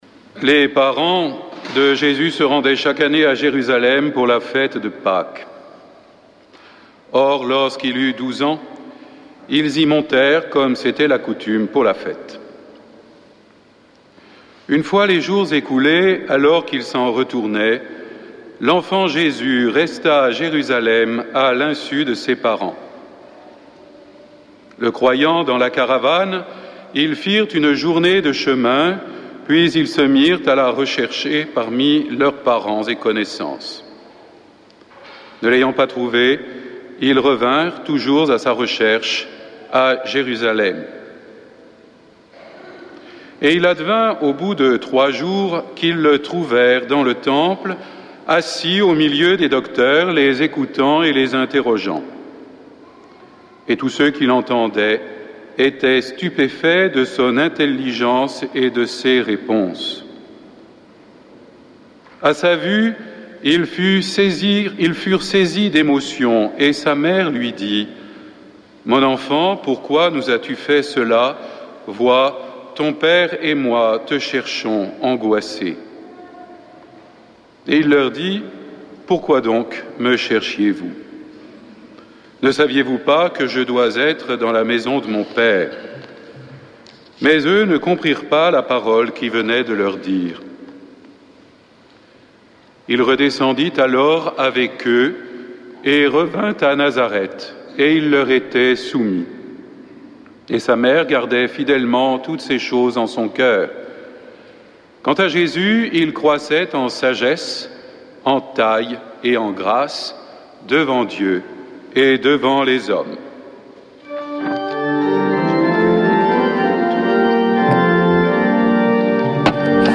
Homélie 29 décembre